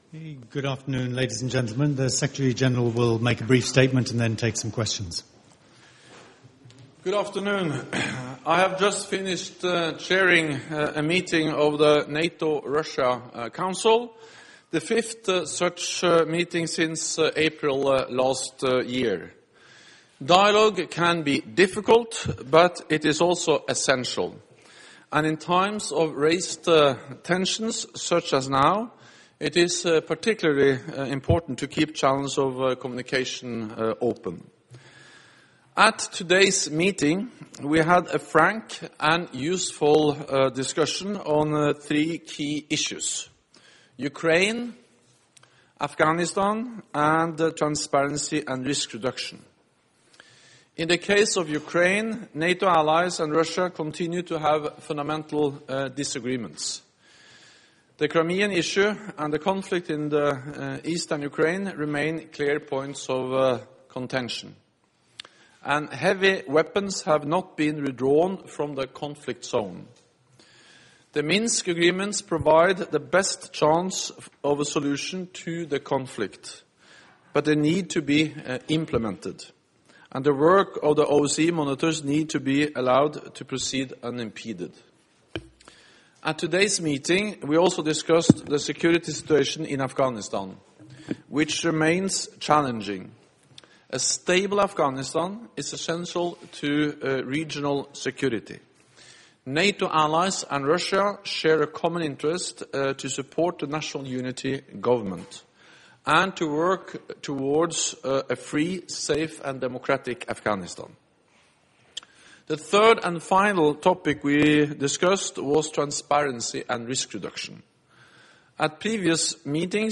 Press point by NATO Secretary General Jens Stoltenberg following the meeting of the NATO-Russia Council